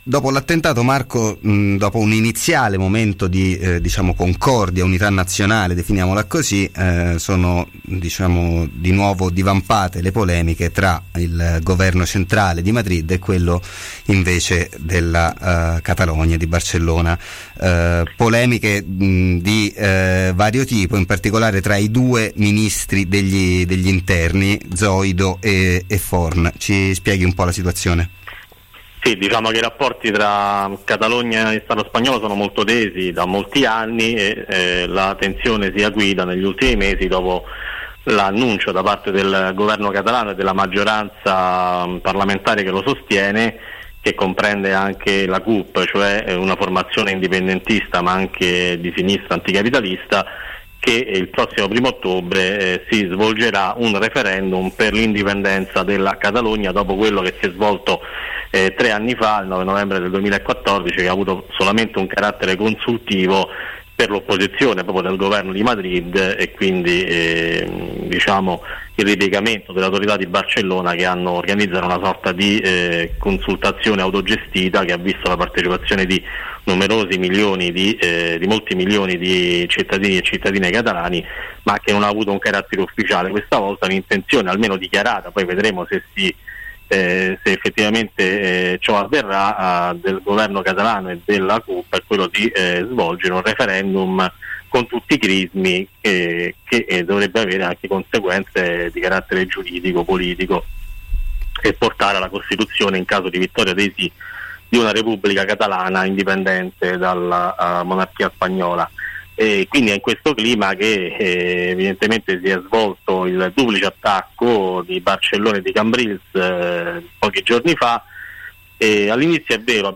Barcellona tra gli attentati e il referendum: intervista